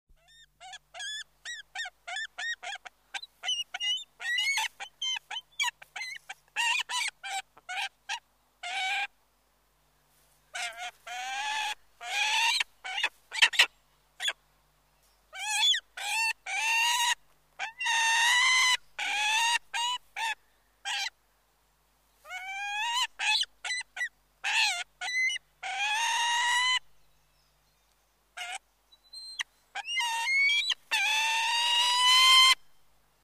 Звуки соколов
На этой странице собраны разнообразные звуки, издаваемые соколами: от громких охотничьих криков до нежного щебетания птенцов.